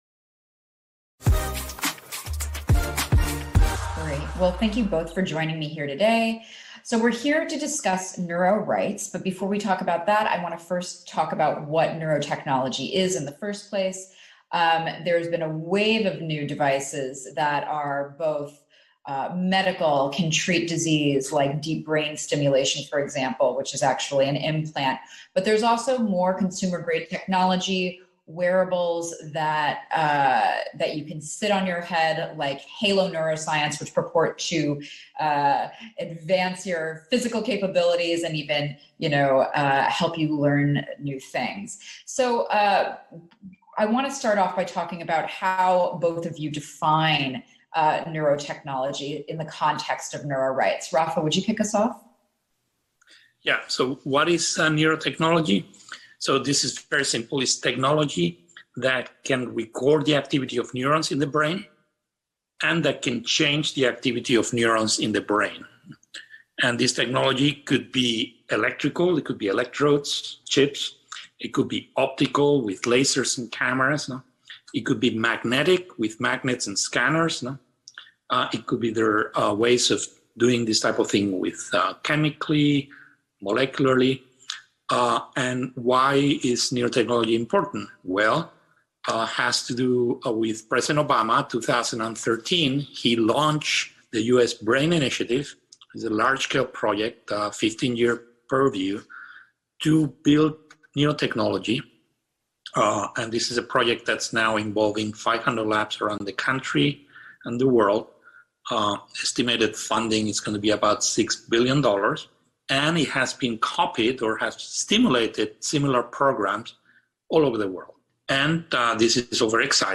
מראיינת